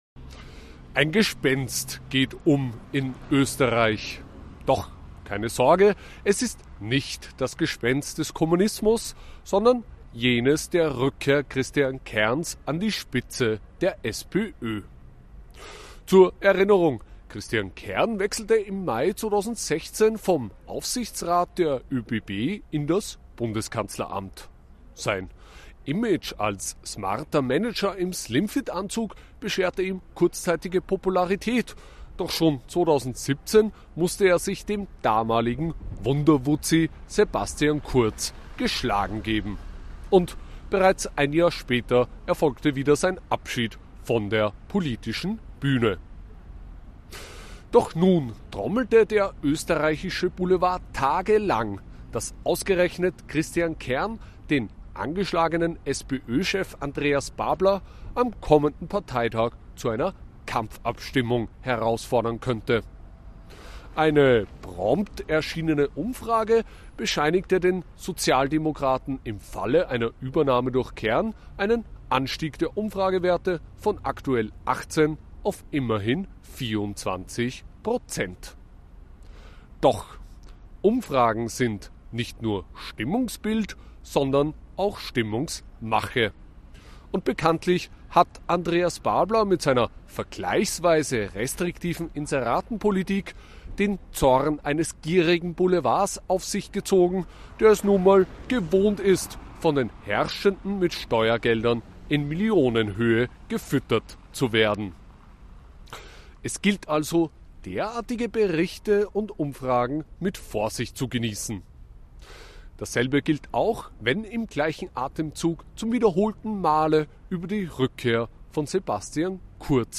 in seinem Kommentar aus Wien.